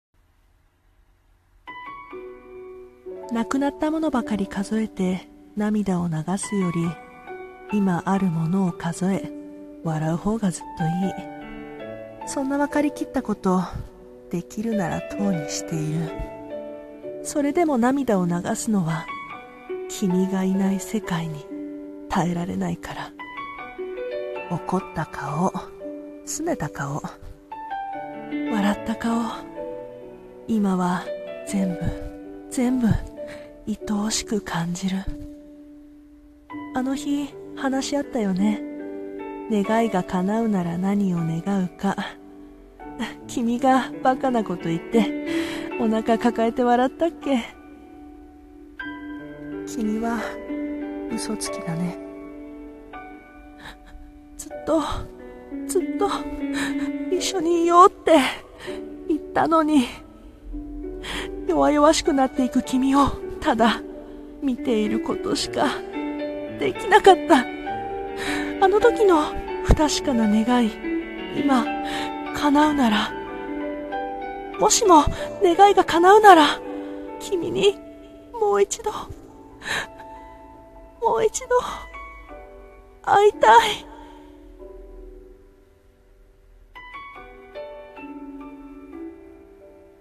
声劇】もしも願いが叶うなら